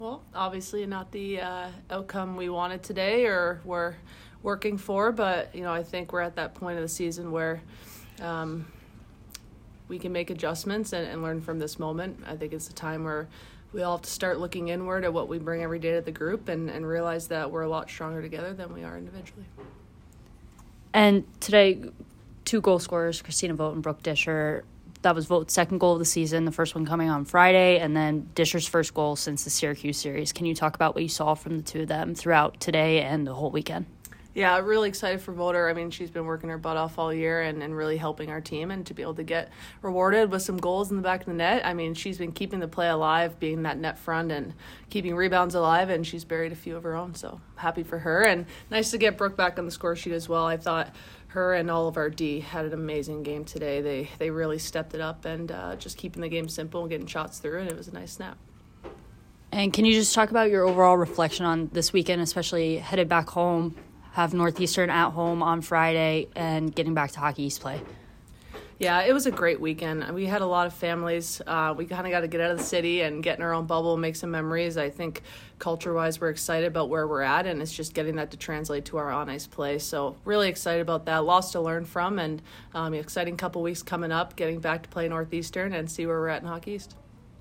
Minnesota State Postgame Interview